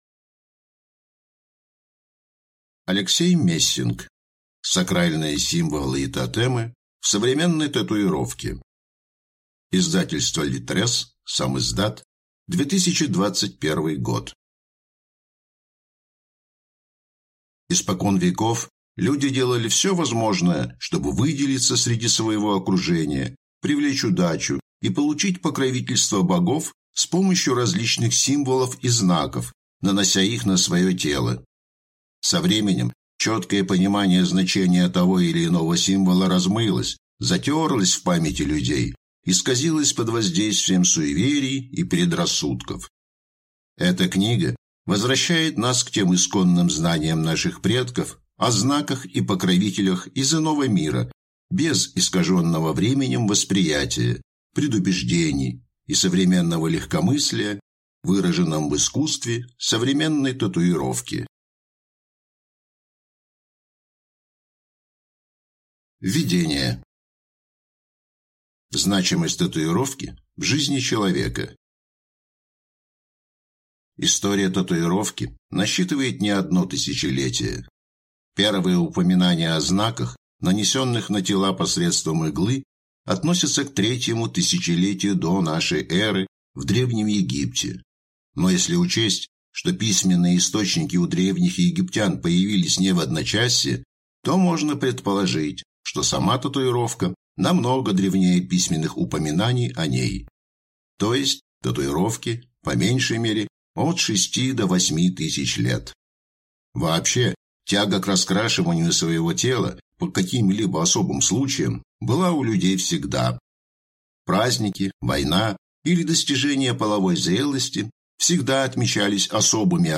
Аудиокнига Сакральные символы и тотемы в современной татуировке | Библиотека аудиокниг